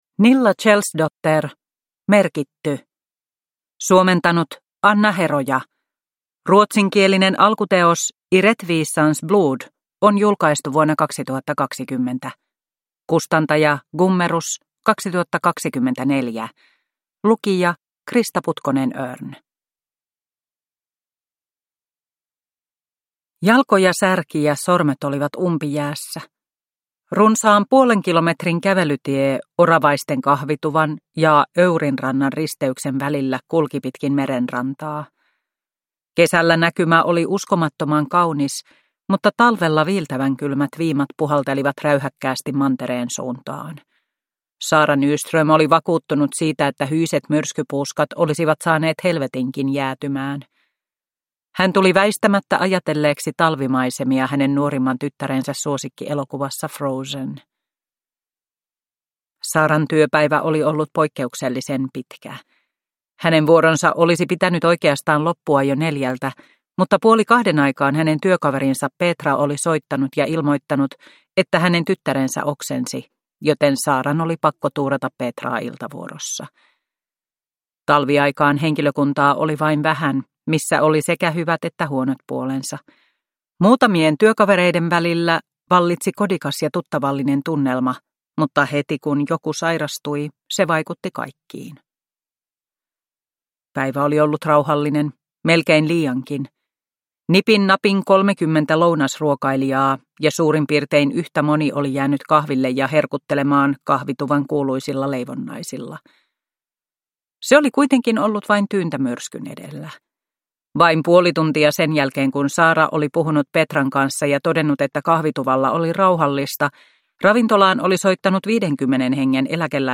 Mija Wadö tutkii 2 (ljudbok) av Nilla Kjellsdotter